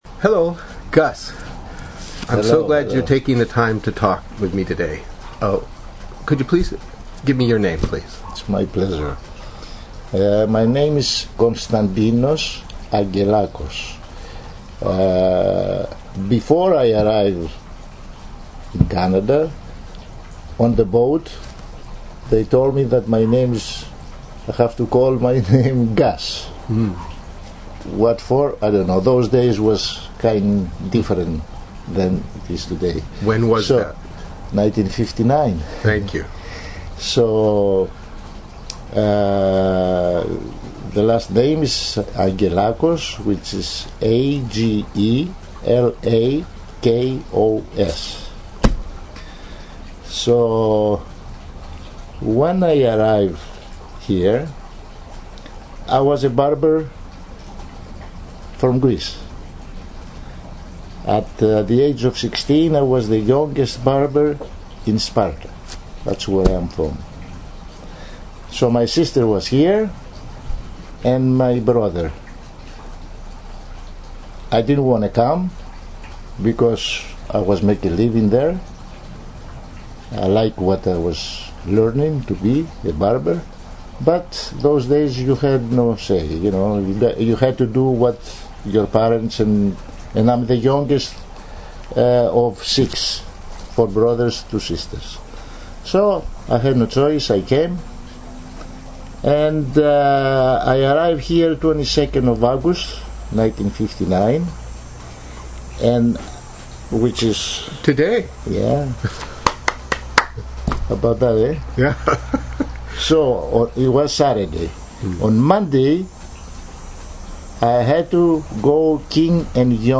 Harbord Village Oral History Project 2013